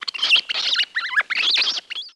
Rat Squeaking, Chattering